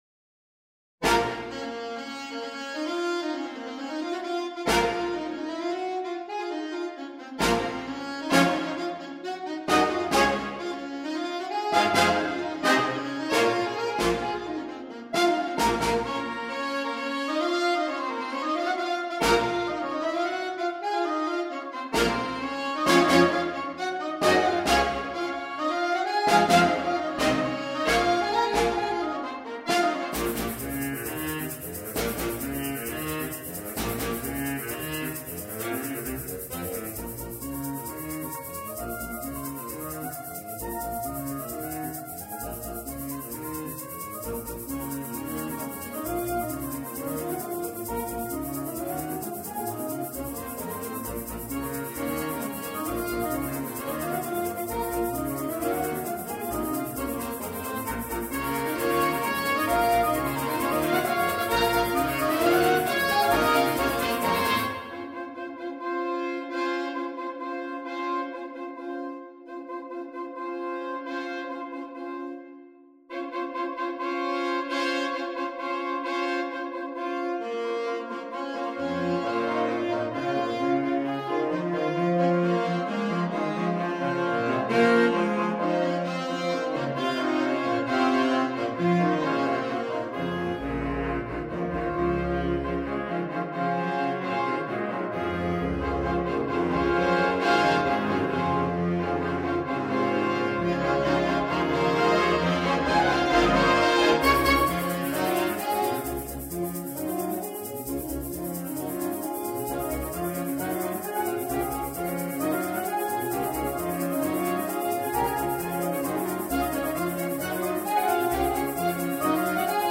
Sno/S . S . S/A . AAA . A/T . TT . BB . B/Bs . 2x Percussion
Exported from the Sibelius score using NotePerformer.